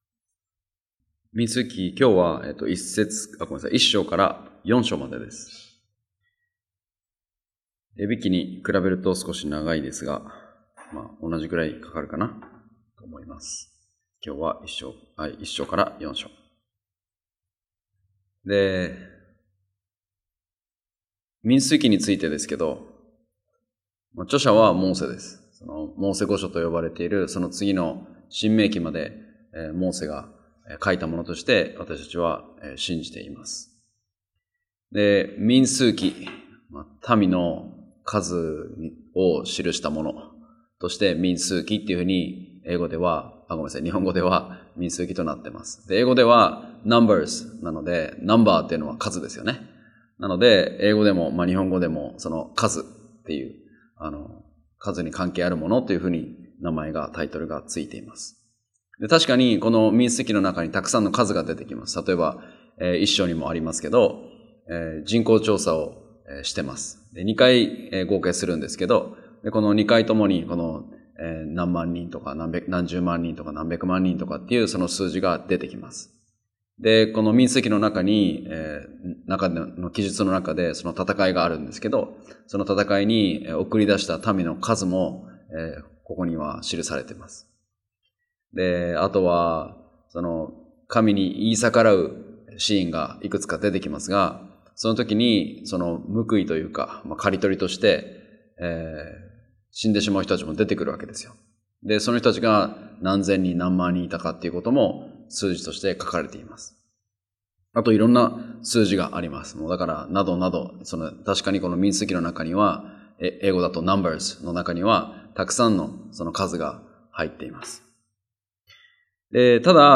木曜バイブルスタディ：民数記
礼拝やバイブル・スタディ等でのメッセージを聞くことができます。